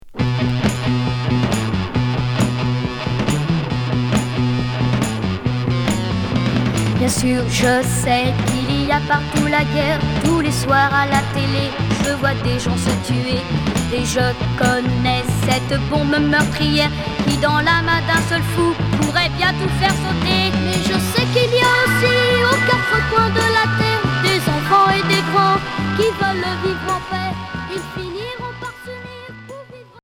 Catholique pop enfantine à fuzz